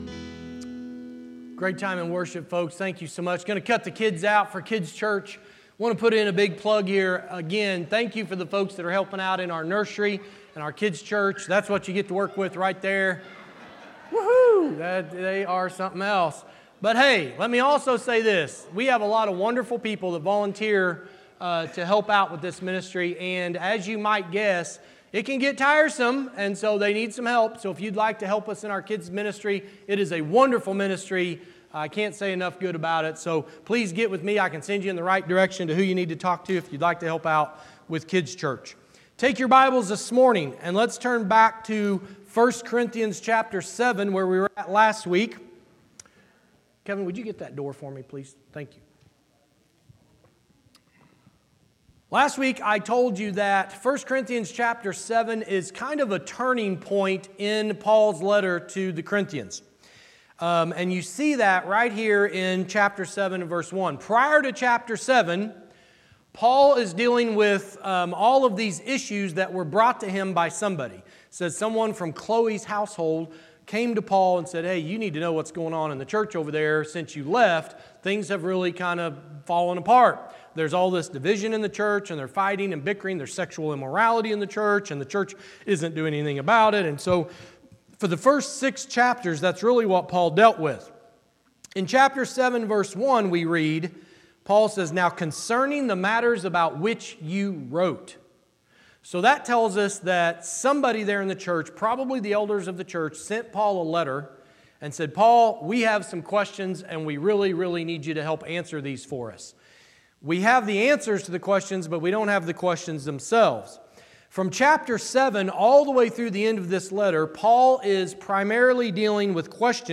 Church in Action Sermon Podcast November 9, 2025 Play Episode Pause Episode Mute/Unmute Episode Rewind 10 Seconds 1x Fast Forward 30 seconds 00:00 / 33:56 Subscribe Share Spotify RSS Feed Share Link Embed